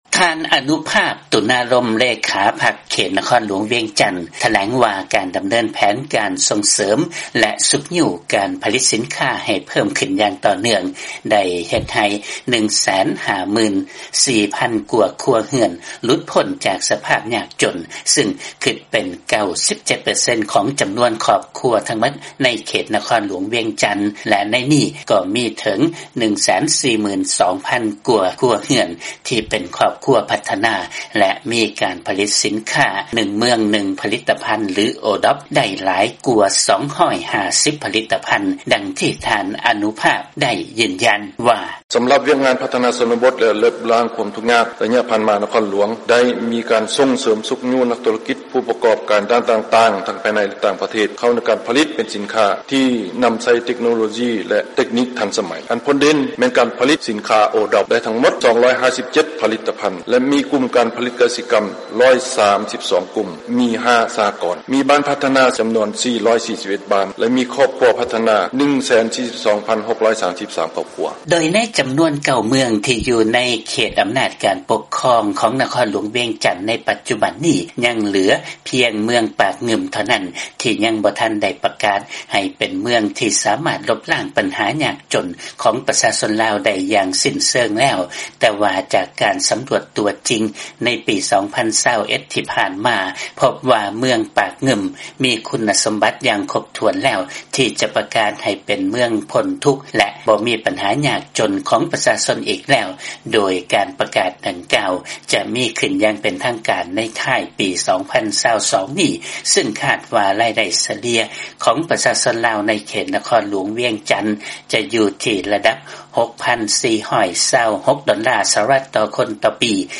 ມີລາຍງານເລື້ອງນີ້ຈາກບາງກອກ.